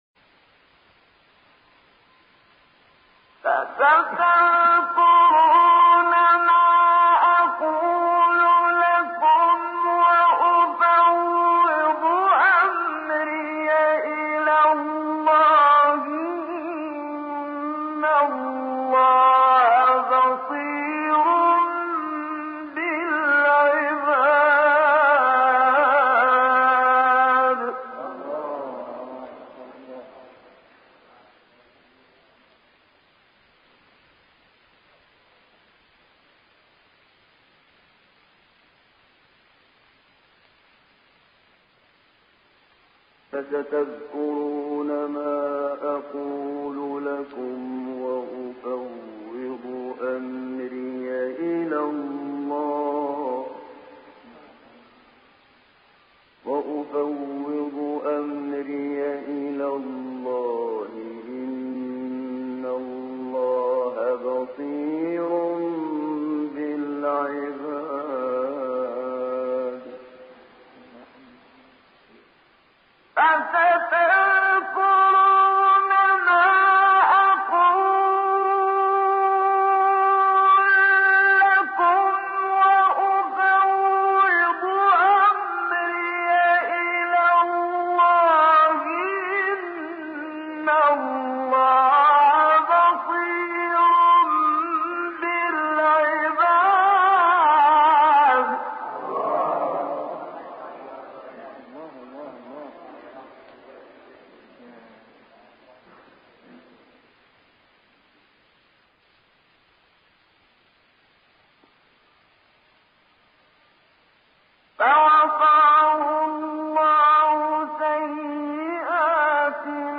منشاوی برای بار دوم آیه را در پرده بم می‌خواند و سپس مجدداً نهاوند را در پرده اوج صدا اجرا می‌کند.